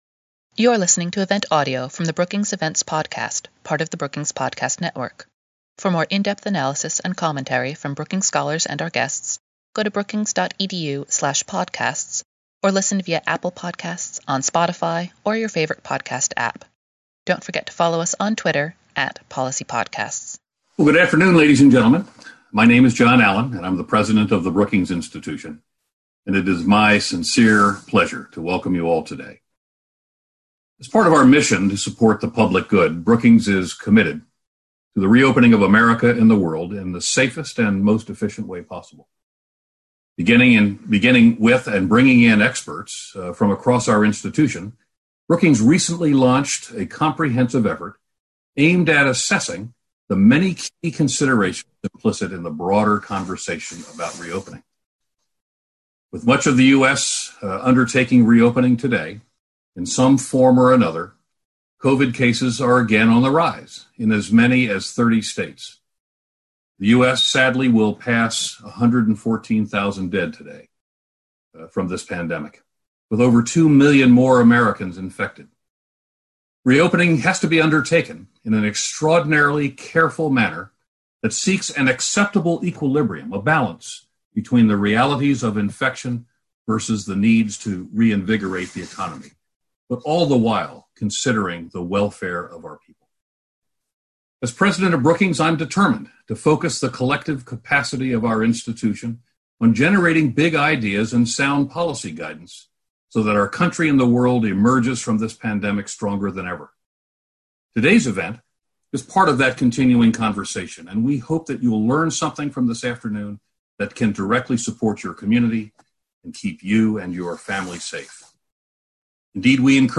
On June 12, the Governance Studies and Metropolitan Policy programs at Brookings cohosted a webinar to discuss equitable solutions for workers and their families as the American economy begins to reopen.